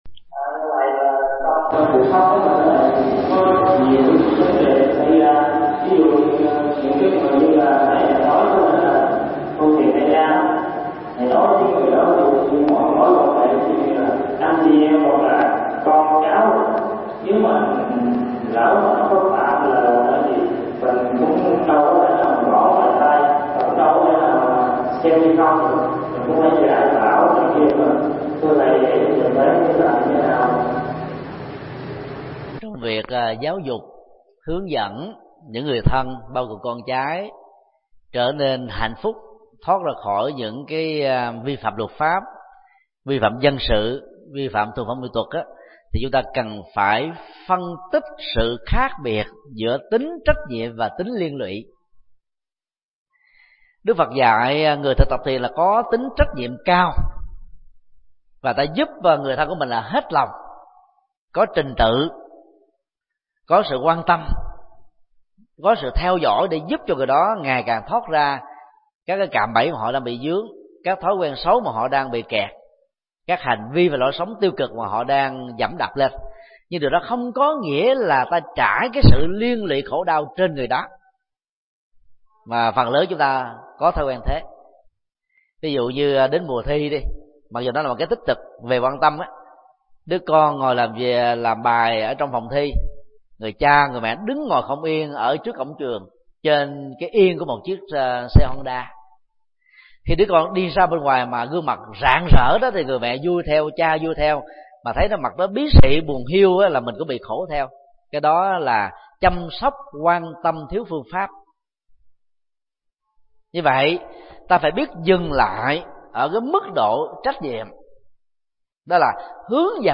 Vấn đáp: Phương pháp giáo dục người thân tránh vi phạm pháp luật – Thầy Thích Nhật Từ